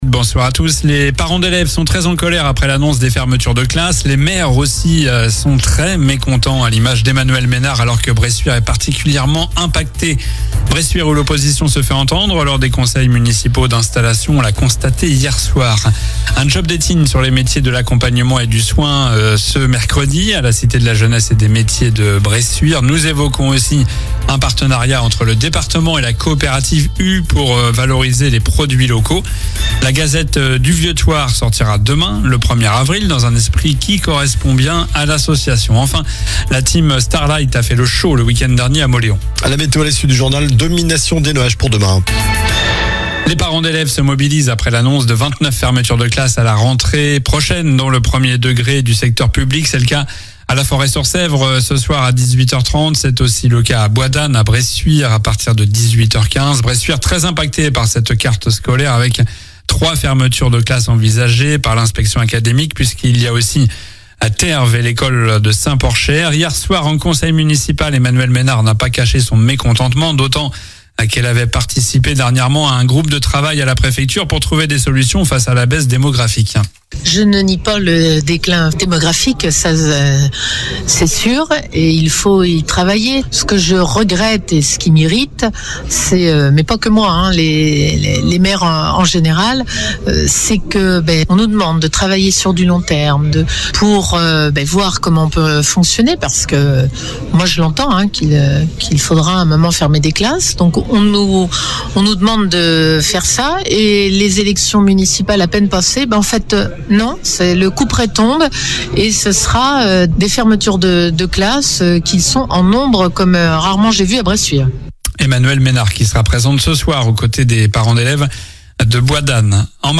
Journal du mardi 31 mars (soir)